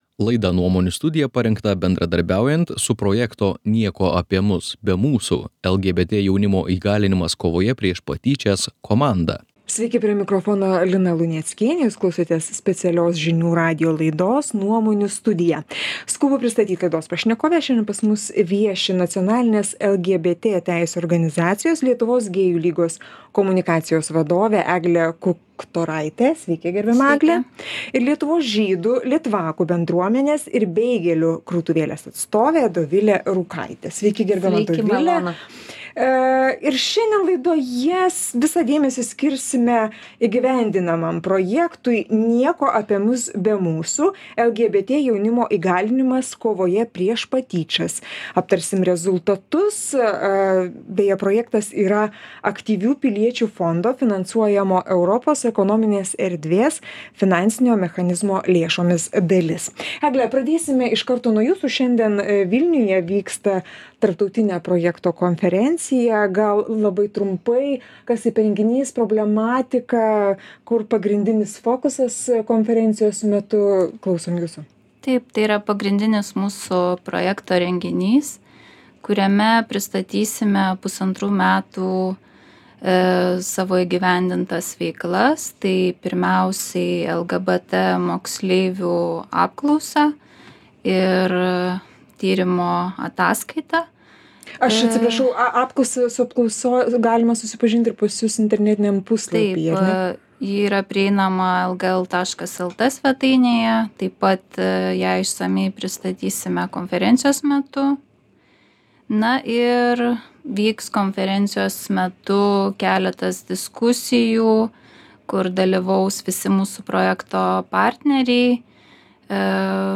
Pokalbis su Nacionalinės LGBT teisių organizacijos